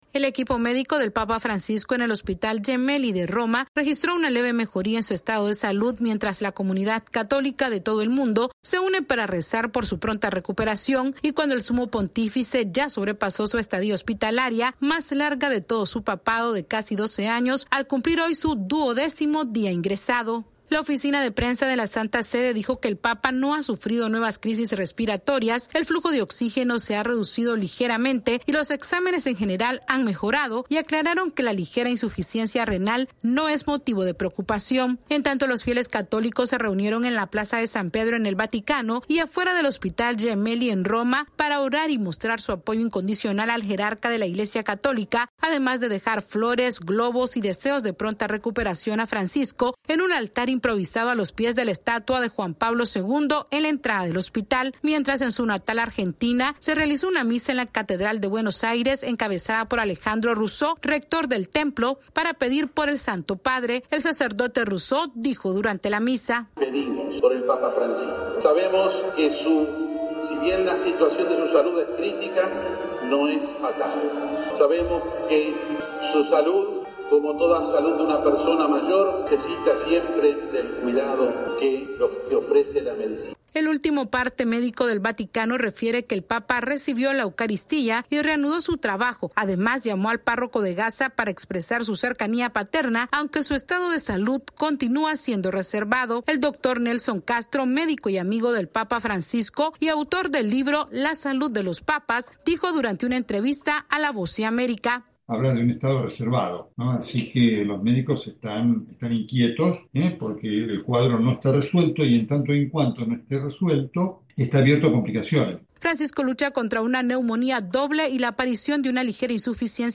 La comunidad católica de todo el mundo se une para orar por la salud del papa Francisco en un momento en que hay esperanza luego de una ligera mejoría en su estado de salud. Esta es una actualización de nuestra Sala de Redacción....